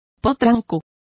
Complete with pronunciation of the translation of colts.